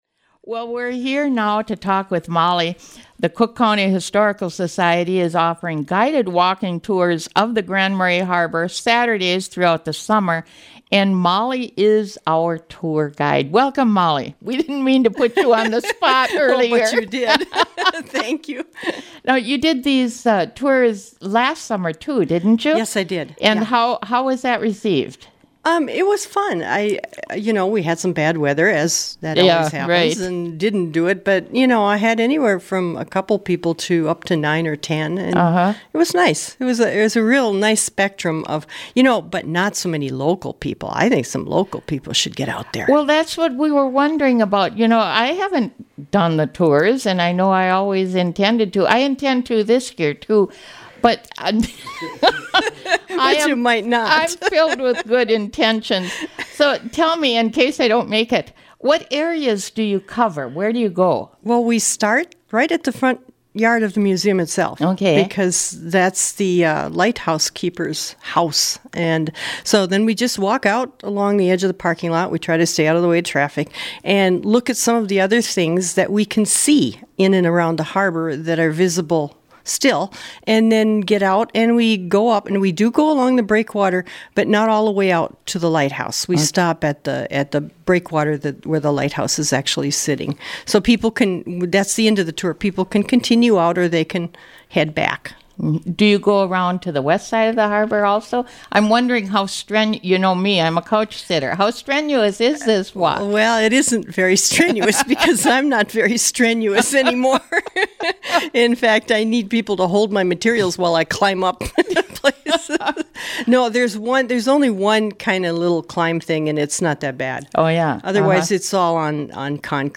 News and information, interviews, weather, upcoming events, music, school news, and many special features.